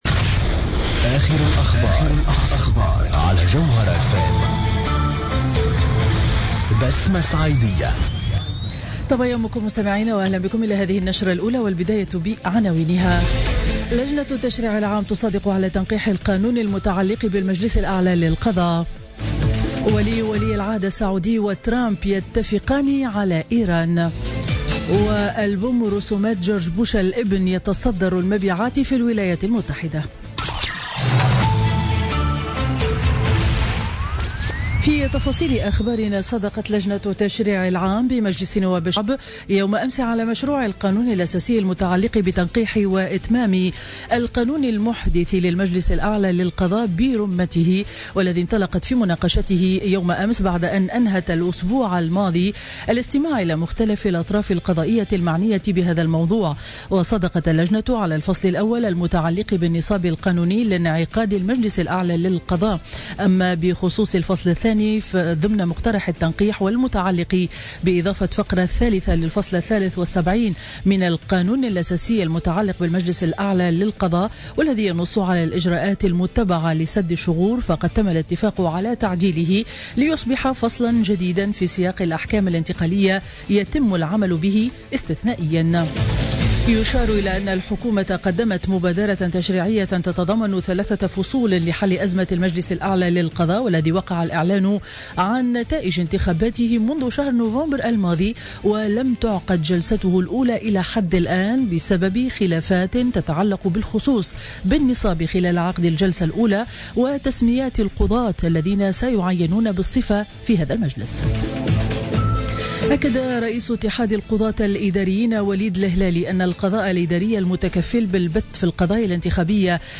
نشرة أخبار السابعة صباحا ليوم الإربعاء 15 مارس 2017